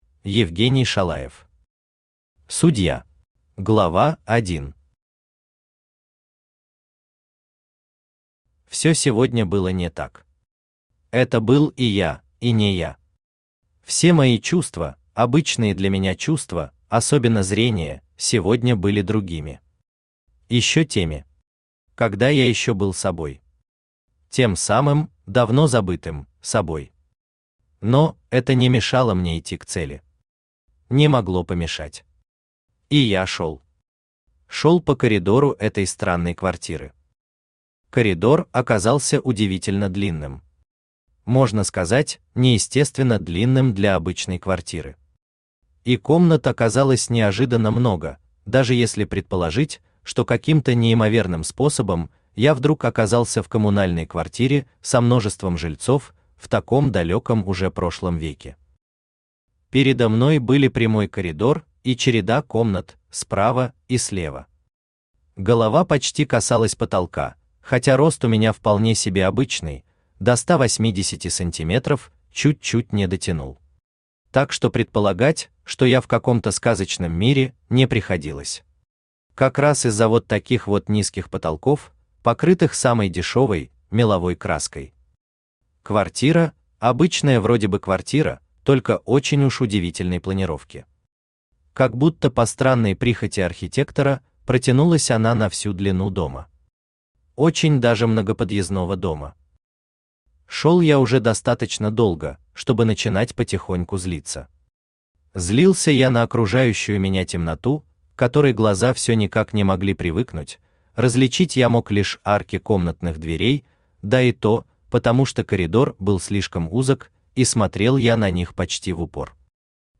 Аудиокнига Судья | Библиотека аудиокниг
Aудиокнига Судья Автор Евгений Викторович Шалаев Читает аудиокнигу Авточтец ЛитРес.